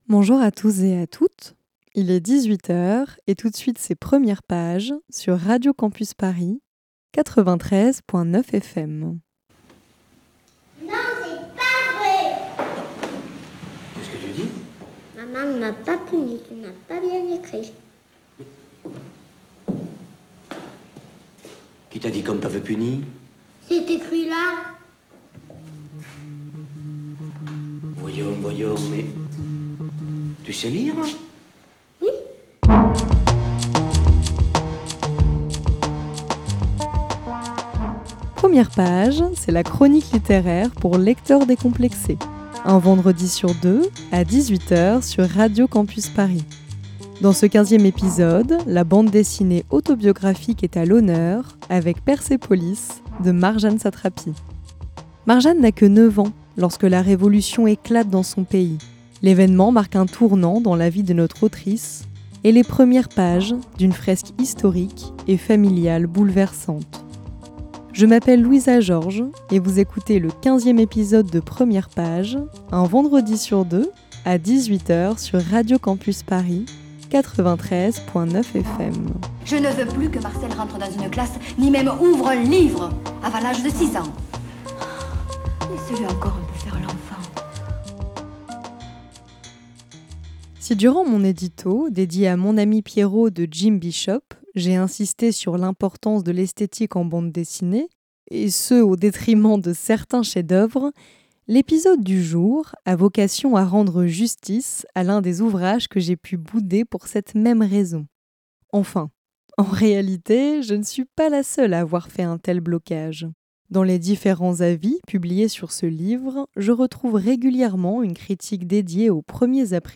Voici en podcast le quinzième épisode de Premières pages, une chronique littéraire pour lecteur.trices décomplexé.e.s, un vendredi sur deux sur Radio campus Paris.